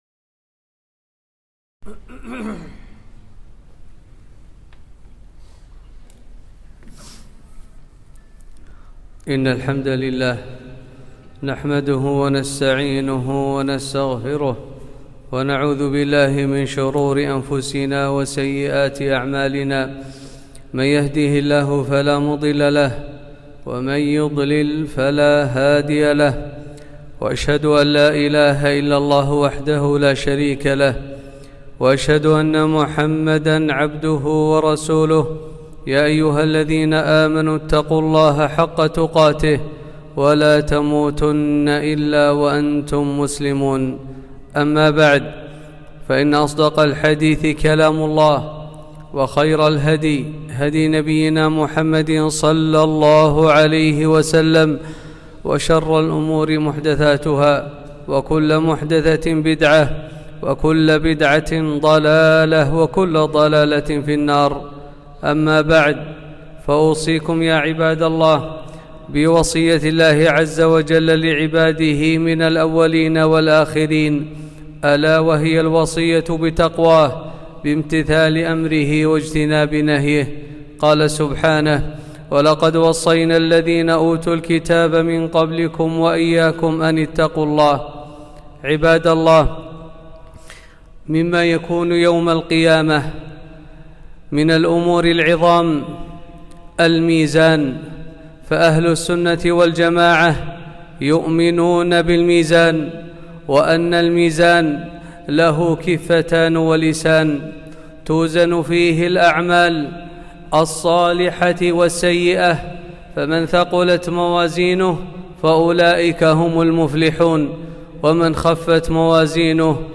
خطبة - الميزان